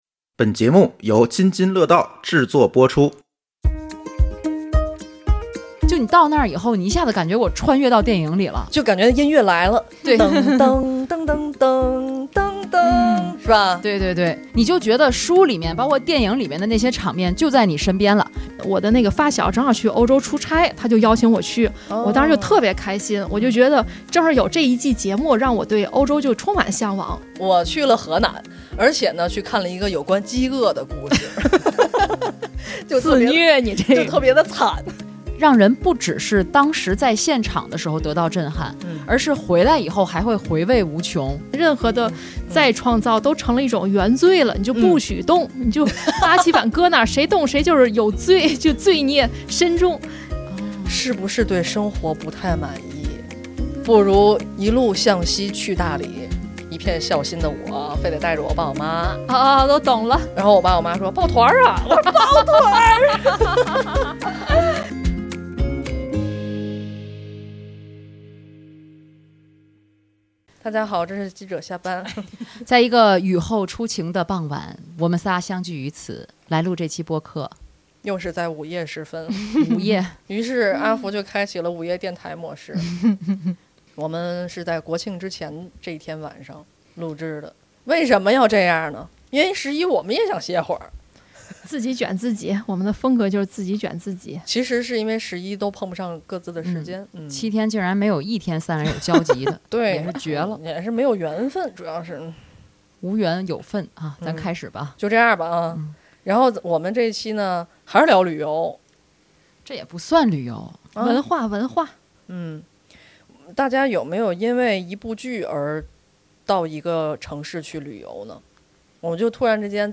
本期节目里，三位主播畅谈戏剧与城市的连接，带你沉浸式走进戏剧的世界，欢迎在评论区或者听友群讲出你的故事……